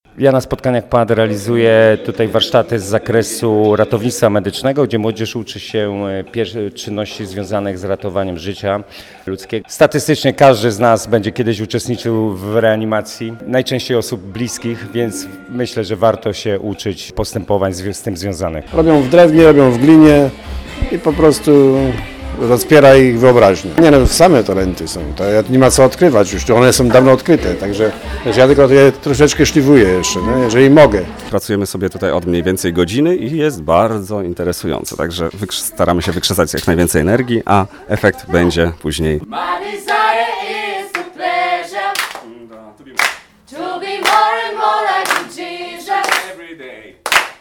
O zajęciach z rzeźby, ratownictwa taktycznego i wokalnych mówili prowadzący je instruktorzy.